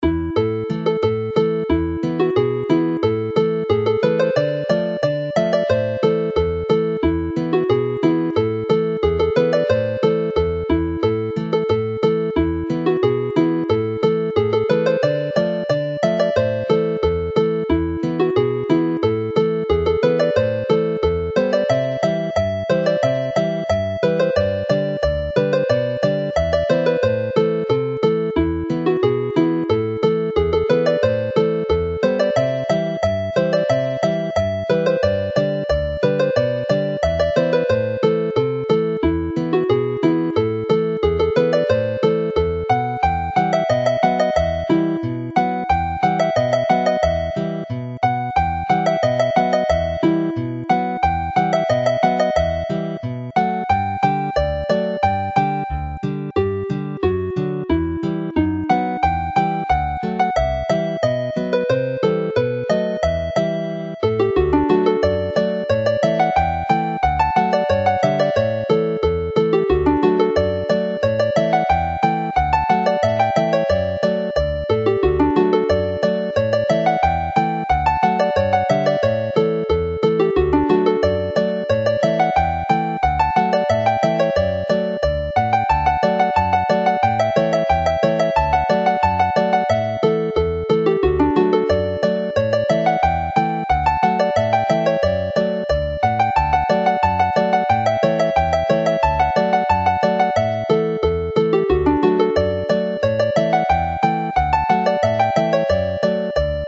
Both tunes can be played as marches or as polkas.
is a suitably lively tune to finish the set.